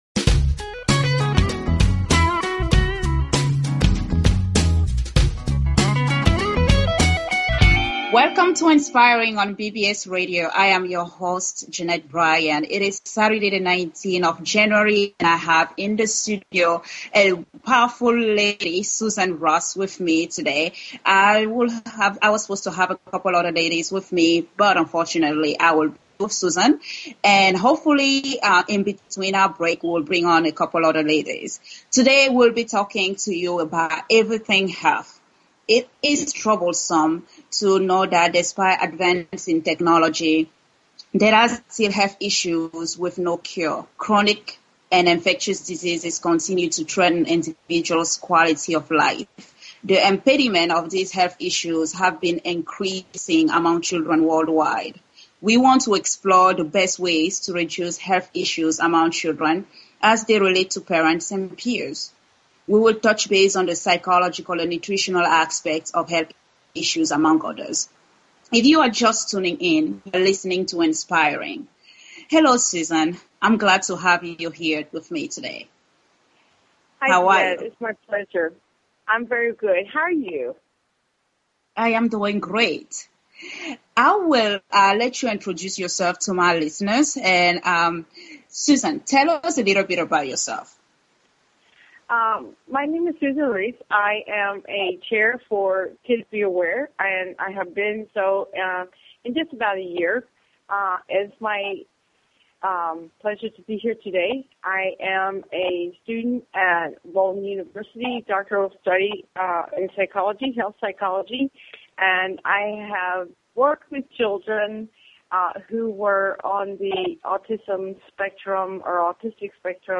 Talk Show Episode, Audio Podcast, Inspiring and Courtesy of BBS Radio on , show guests , about , categorized as